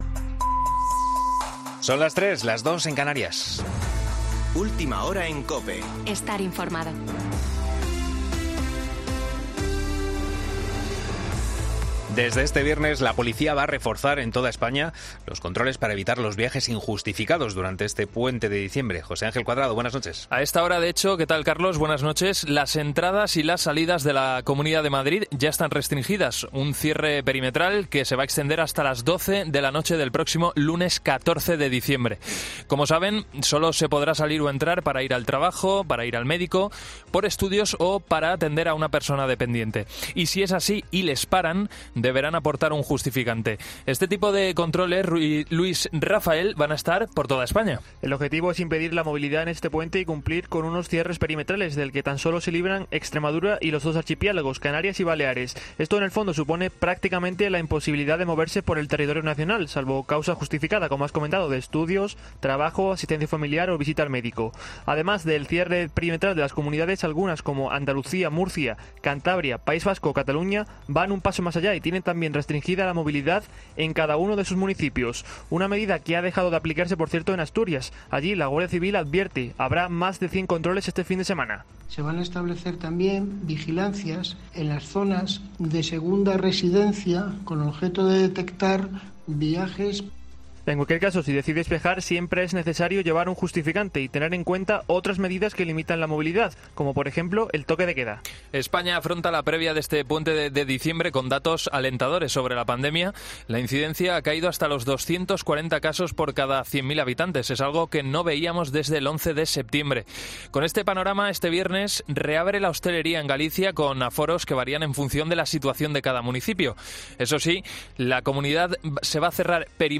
Boletín de noticias COPE del 04 de diciembre de 2020 a las 03.00 horas